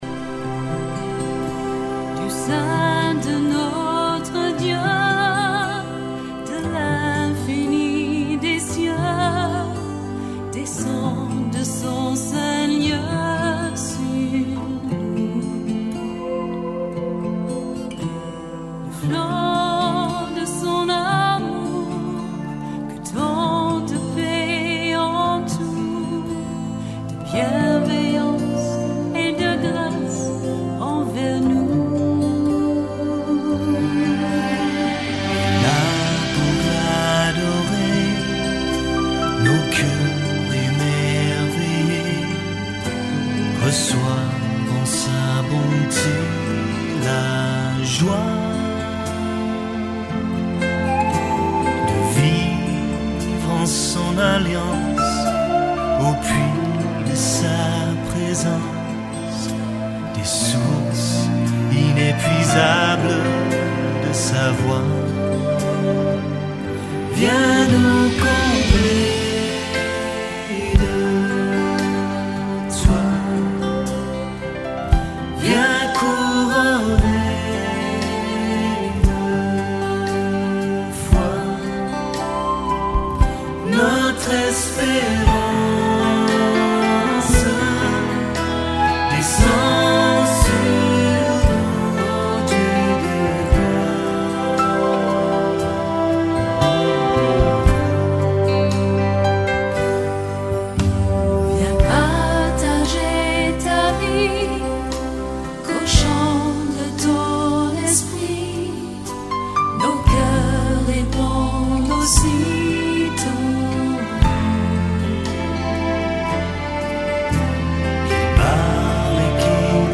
MP3 / Korg / Guitare / Flute et chant / ....video